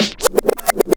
Roll_Spinback.wav